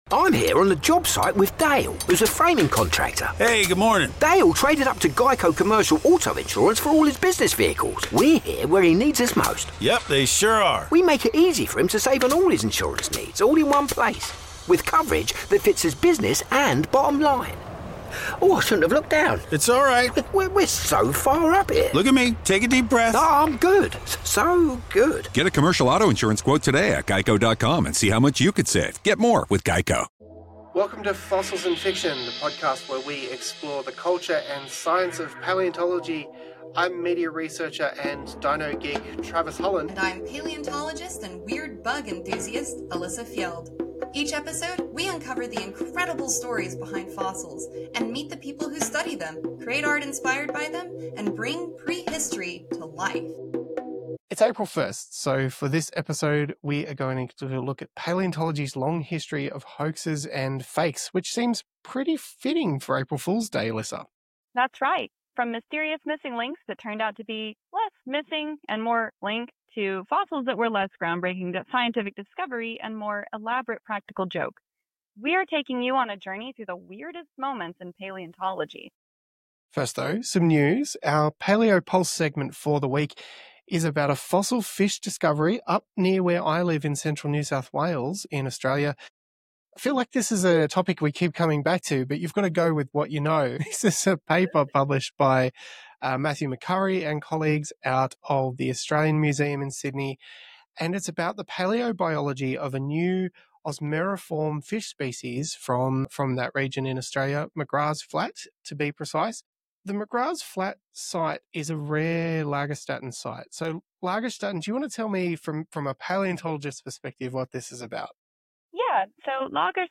This conversation explores the fascinating and often humorous world of paleontology hoaxes, from the infamous Piltdown Man to the Cardiff Giant.